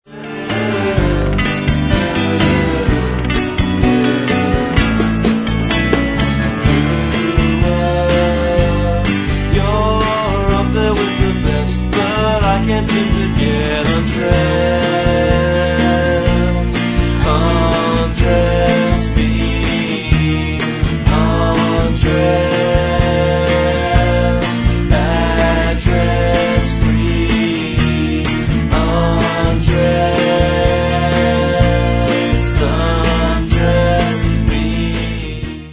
Haunting mellow songs
dual vocals
bittersweet tunes